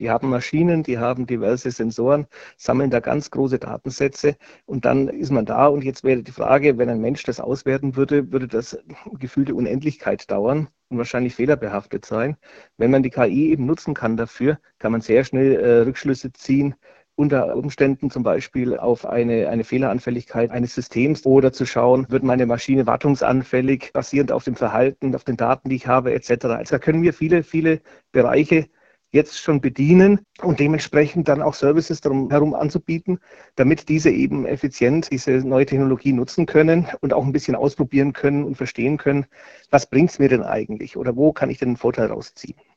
Interview mit dem SWR